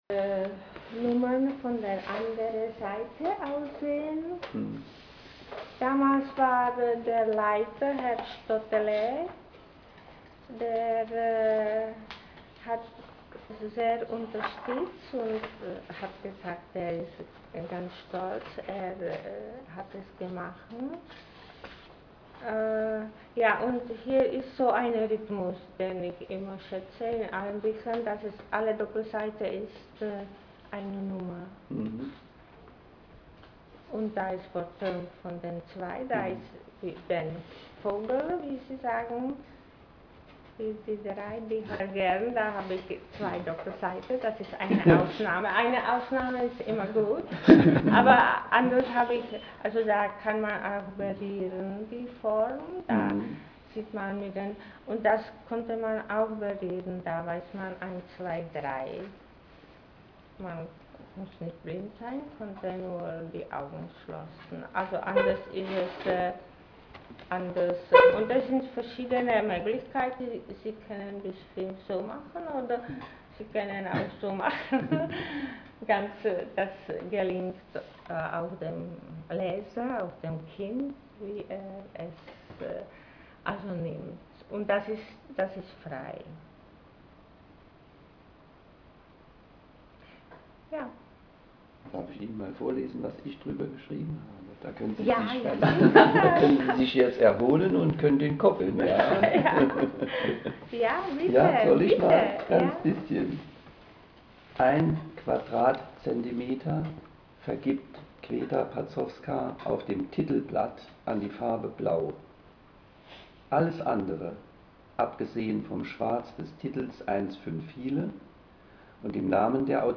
Ihre jugendliche Stimme, ihr für sie so charakteristisches, keckes, mädchenhaftes ´okay´ am Ende des Gespräches, ziehen den Alten bis heute in den Bann.